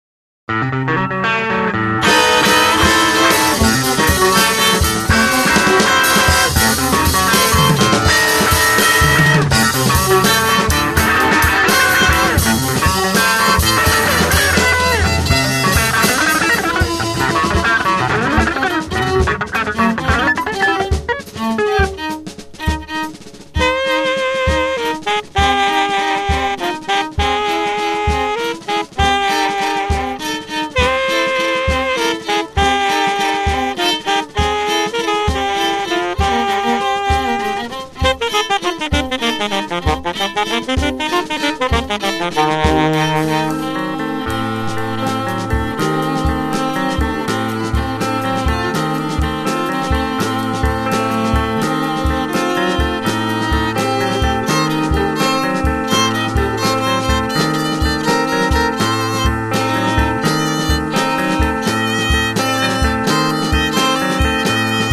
guitar and drums
avantjazz saxophonist